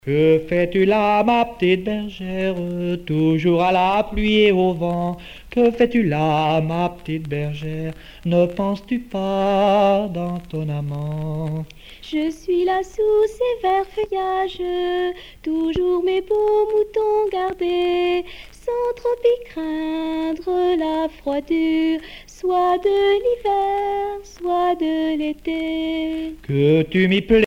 Genre dialogue
Musique traditionnelle paysanne du Haut-Poitou
Pièce musicale éditée